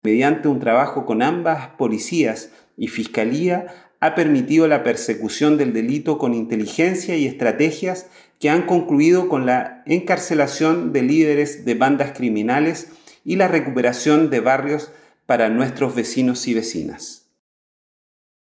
Por su parte, el alcalde de la comuna de Pedro Aguirre Cerda, Luis Astudillo, abordó que Seguridad Ciudadana y Carabineros han estado realizando patrullajes mixtos, fiscalizaciones vehiculares y en el transporte público, operativos de retiros de rucos, y el Plan Ruta Segura, con foco en la locomoción colectiva y establecimientos educacionales.